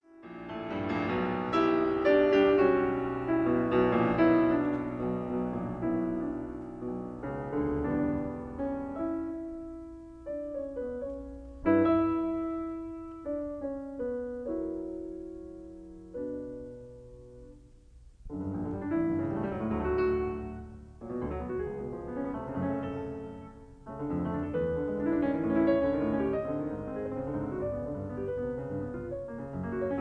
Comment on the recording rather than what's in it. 1959 stereo recording